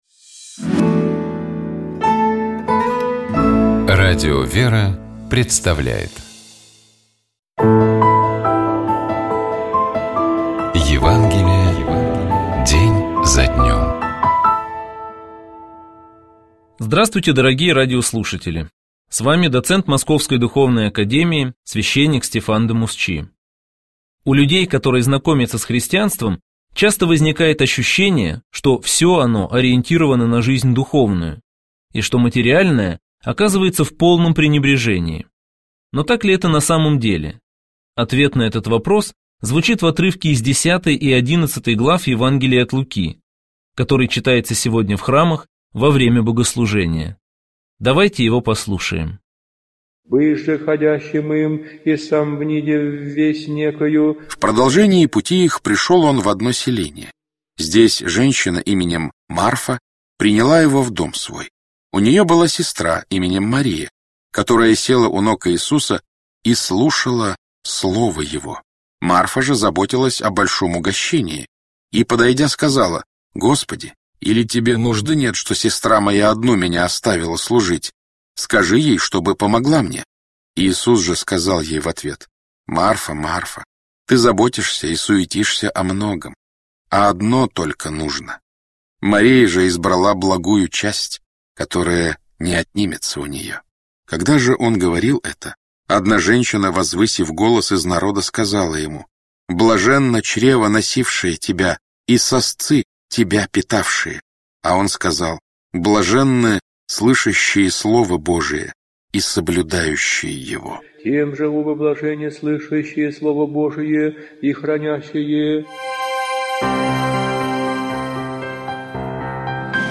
Читает и комментирует
епископ Переславский и Угличский Феоктист